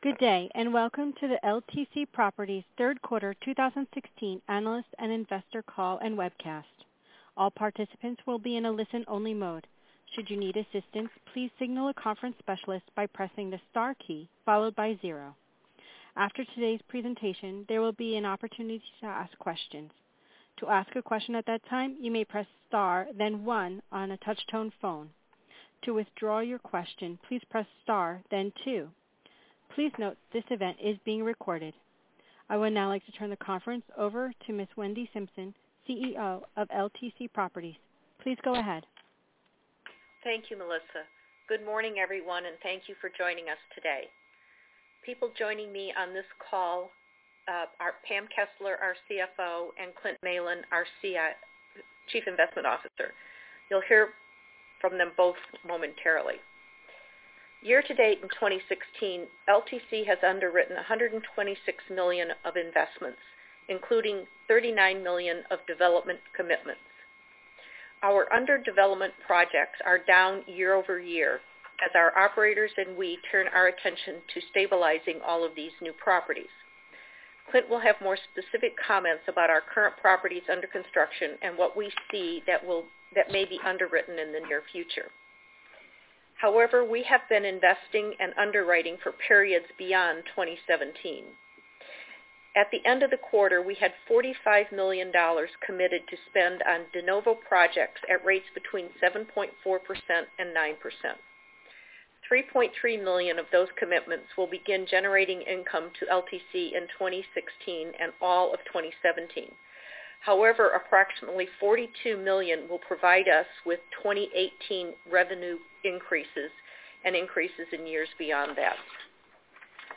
Earnings Webcast Q3 2016 Audio